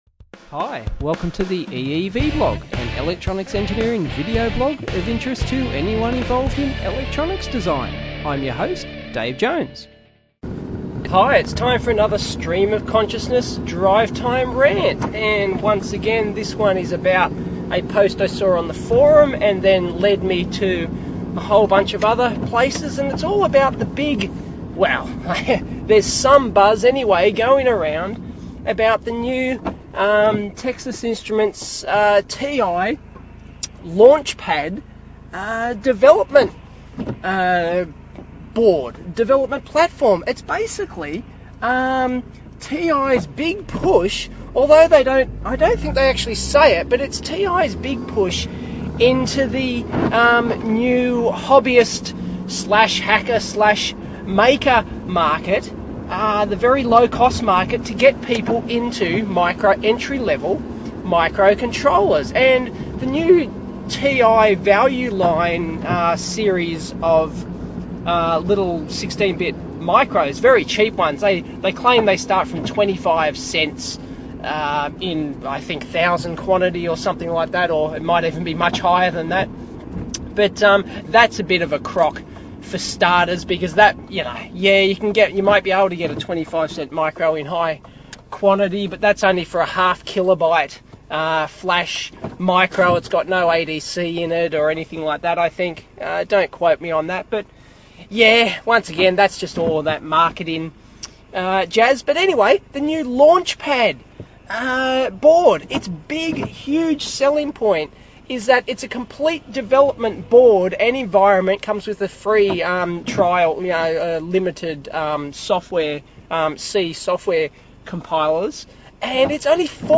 Drive-Time.